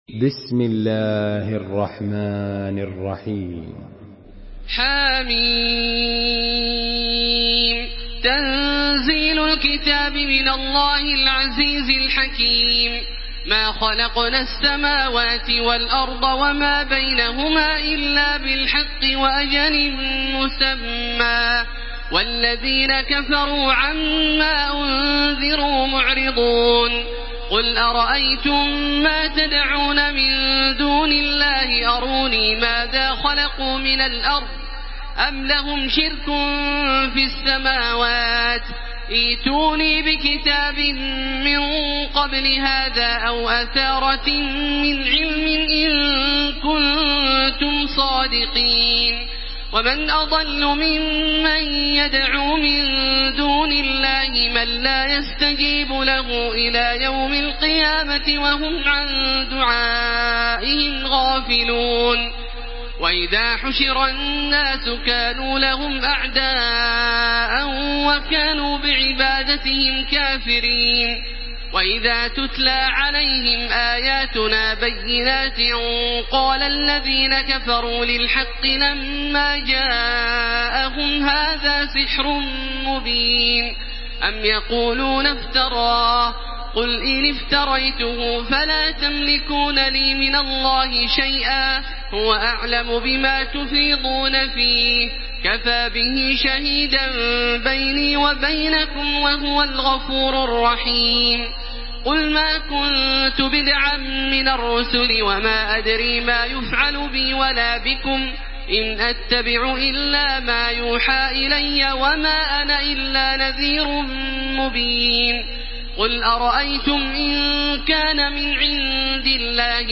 سورة الأحقاف MP3 بصوت تراويح الحرم المكي 1434 برواية حفص
مرتل